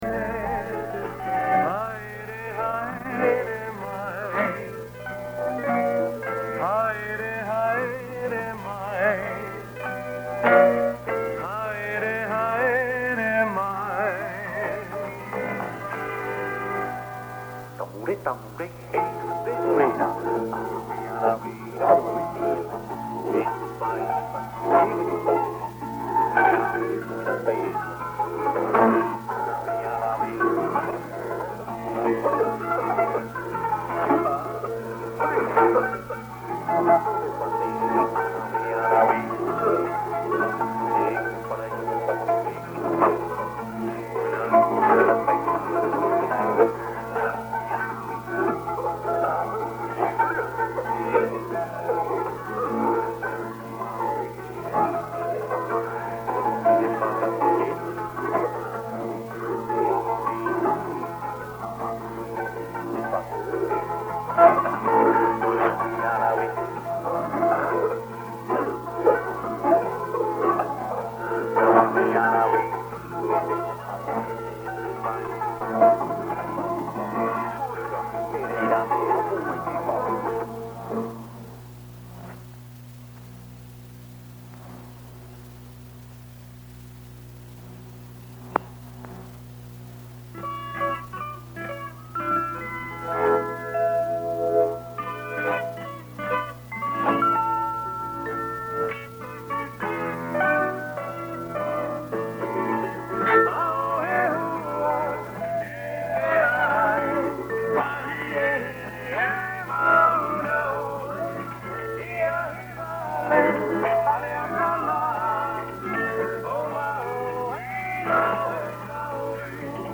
Saturday evenings were a good time to tune in, because of a music program that aired with a good selection of island music. The program had an announcer who spoke in the island vernacular (Tahitian?), and when that program ended they switched to French. Here is a 30-min recording of Radio Tahiti on 15170 kHz from a while ago, most likely around one of the solar maxima of either 1980 or 1991.
This would be recorded either with the DX-160 or a DX-302. Apologies too for the jump in volume at around the 2:37 mark. So close your eyes, imagine you are lying in a hammock on a beach somewhere in the South Pacific, with a warm breeze off the ocean and your favourite cooled beverage nearby, listening to some of the best island music anywhere.